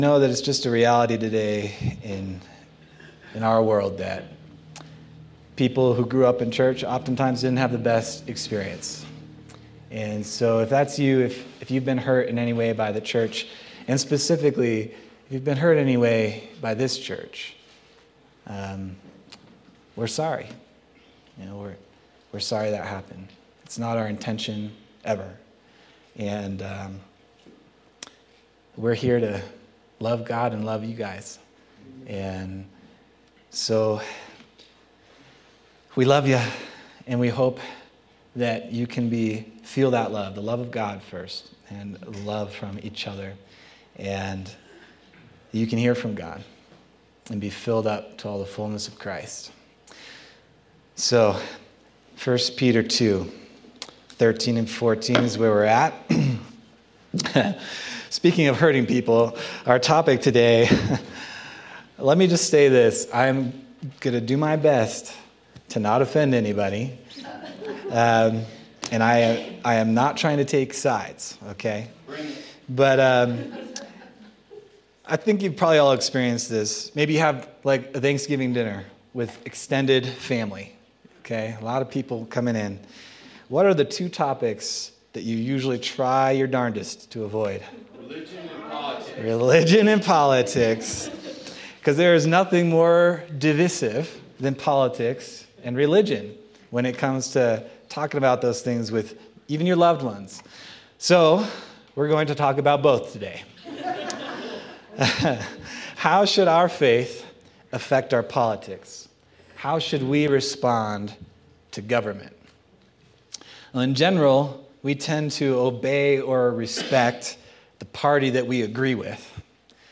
March 2nd, 2025 Sermon